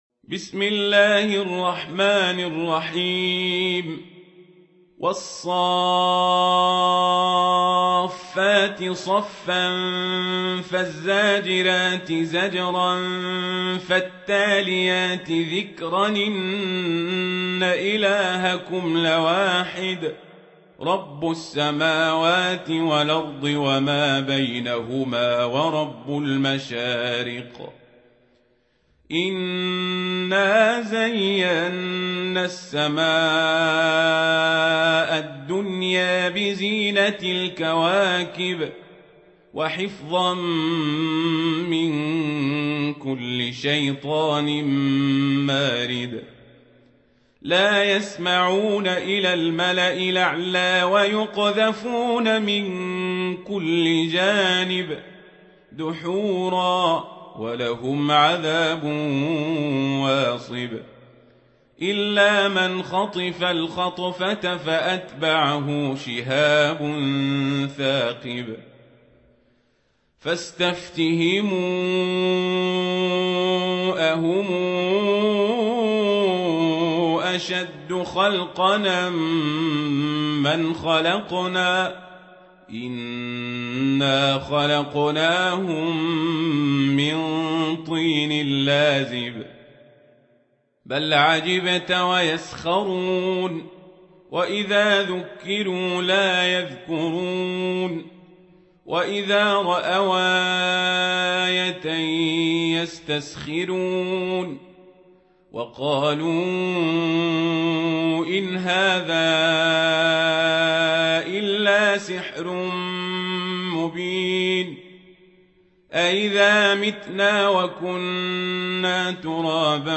سورة الصافات | القارئ عمر القزابري